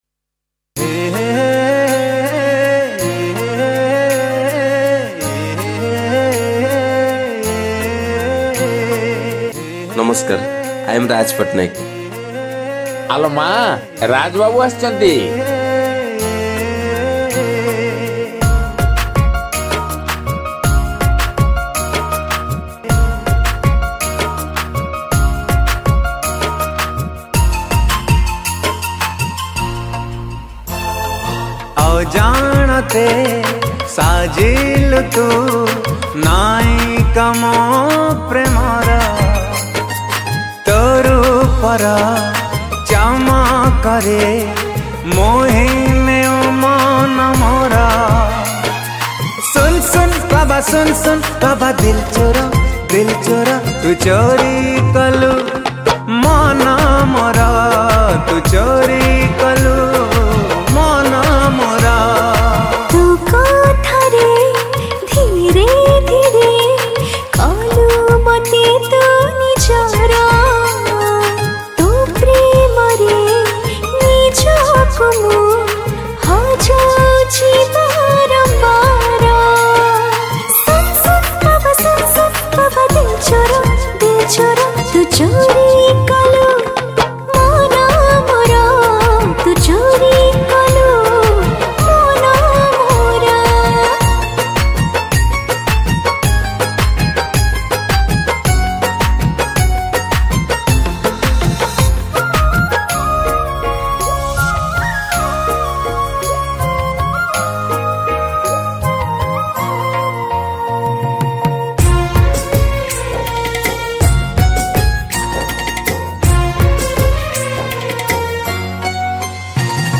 Keyboard Programming